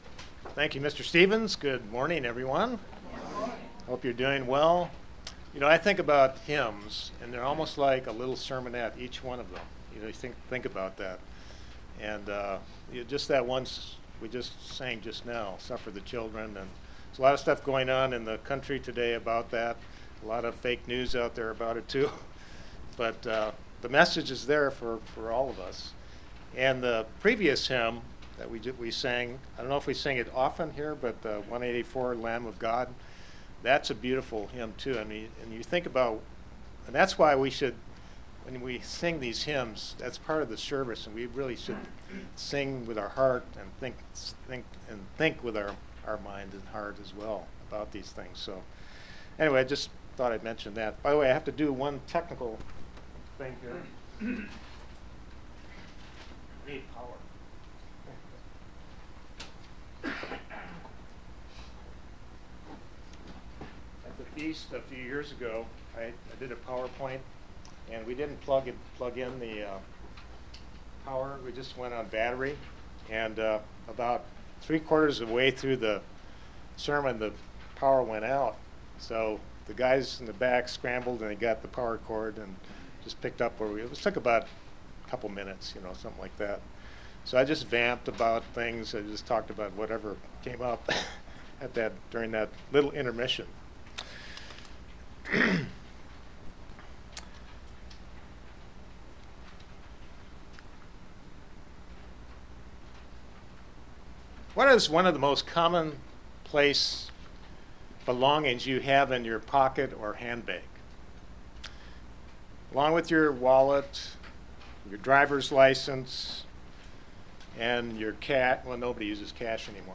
Sermons
Given in Kingsport, TN Knoxville, TN London, KY